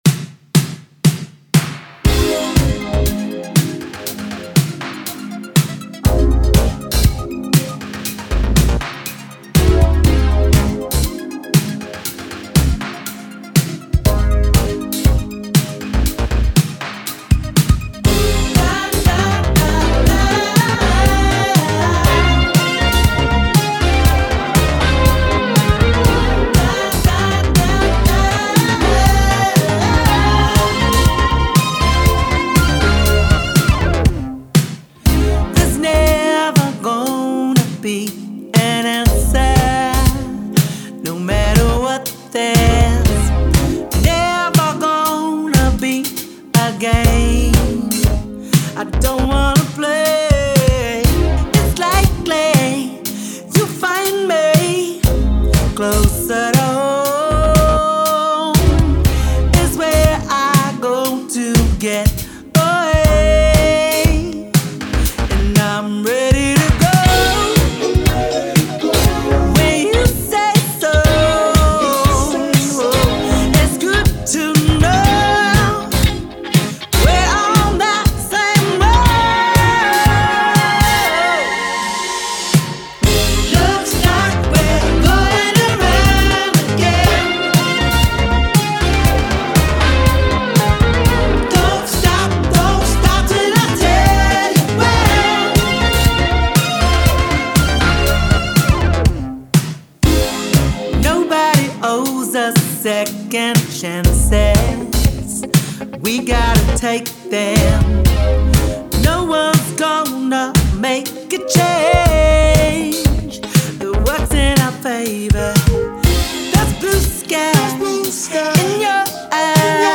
Genre : Electro Funk, Soul, RnB.